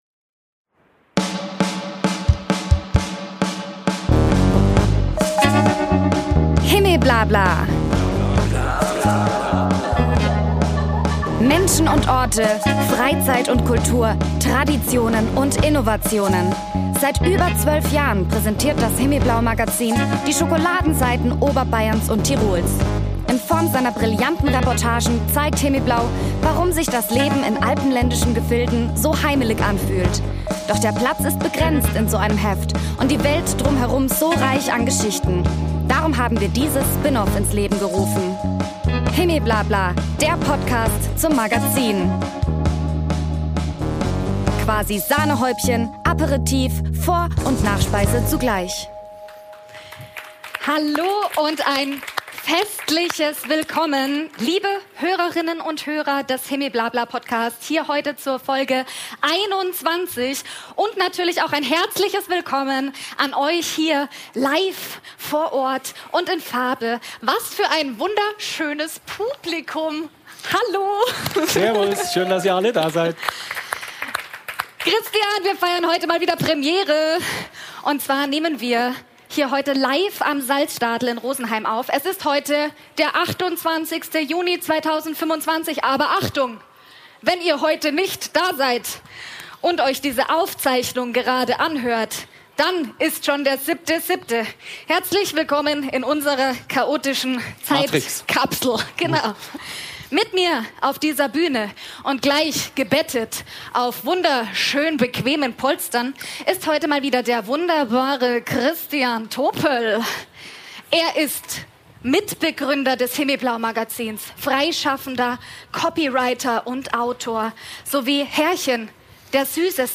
Folge 22: Die Live-Show mit Stargast Florian "Meister Eder" Brückner. Aufgenommen am 28.06.2025 im Rahmen des Rosenheimer Stadtfests